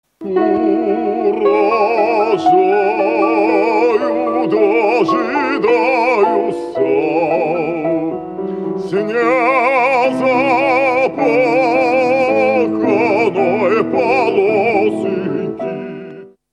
Эту колыбельную о нелёгкой крестьянской жизни Модест Мусоргский написал на одноимённое стихотворение Николая Некрасова.